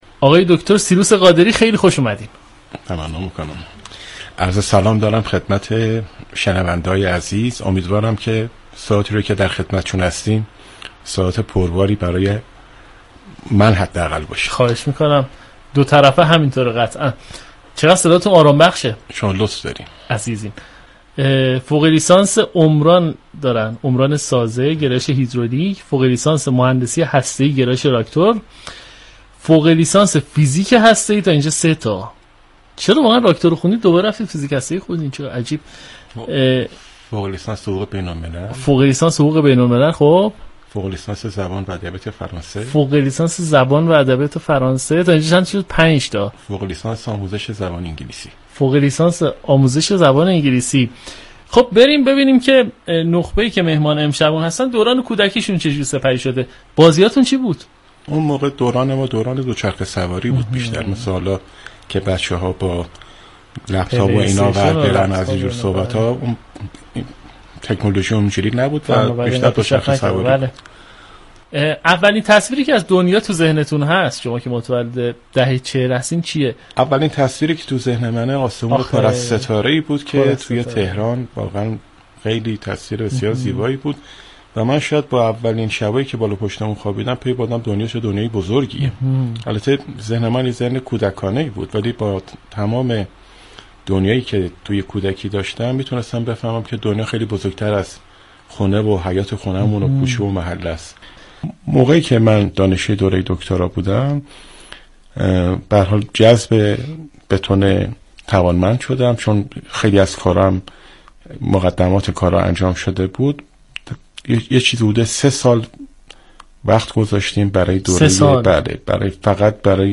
در ادامه شنونده گلچین این گفتگو باشید.